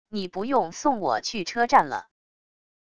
你不用送我去车站了wav音频生成系统WAV Audio Player